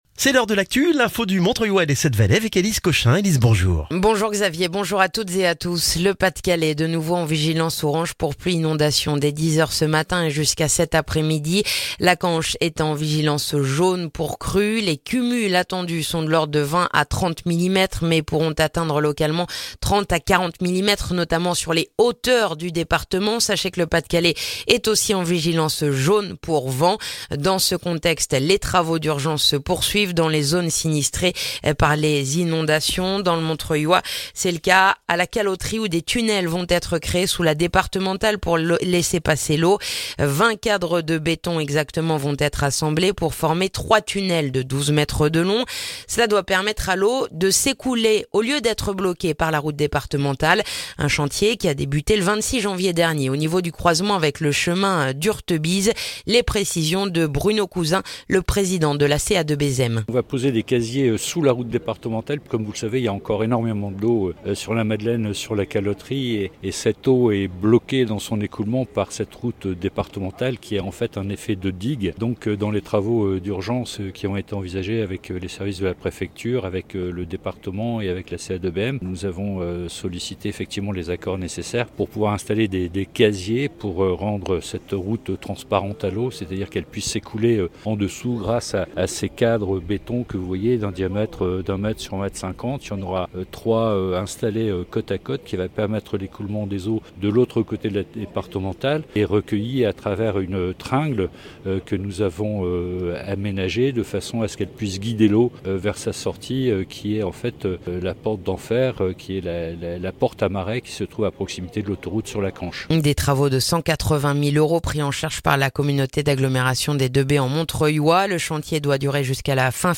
Le journal du mercredi 7 février dans le montreuillois